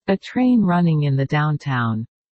（音声は個人的にパソコンを使って作成したもので、本物の話者ではありません。